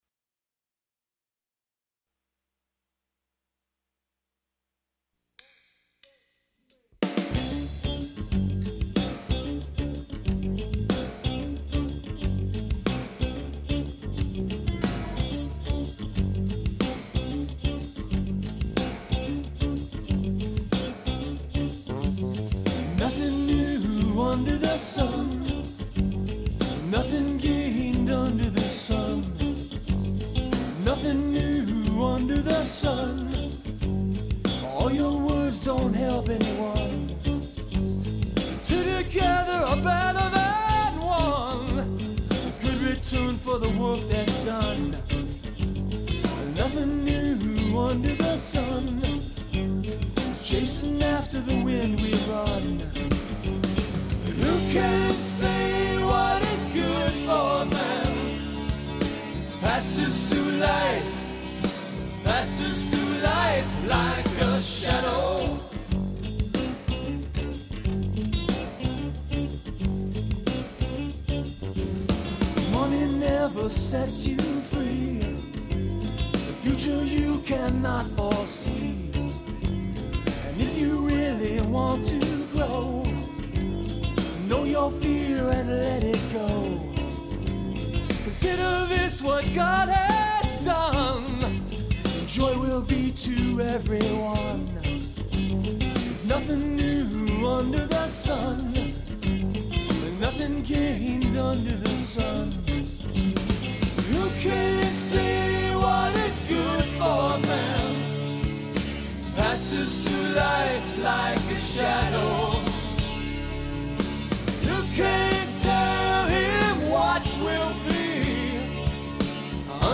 on digital VHS